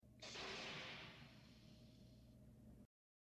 The Black Hole FX - Cygnus door opens
The_Black_Hole_FX_-_Cygnus_door_opens.mp3